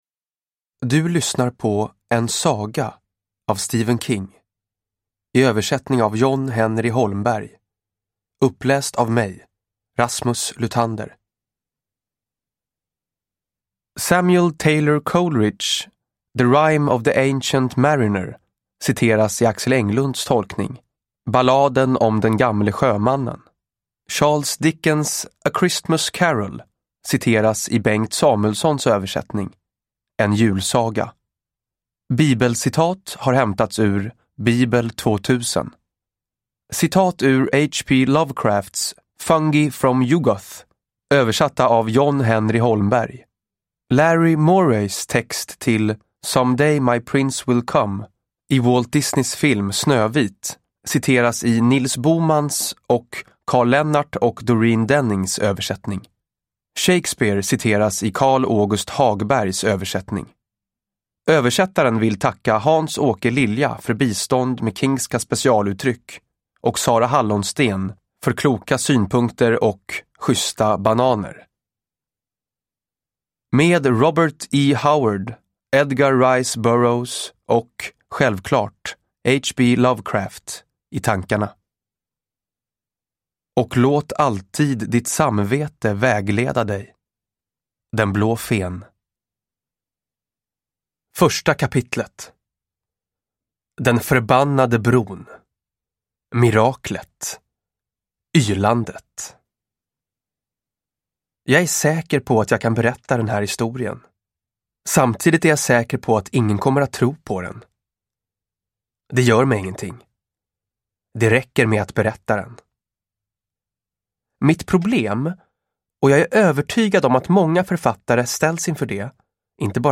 En saga – Ljudbok – Laddas ner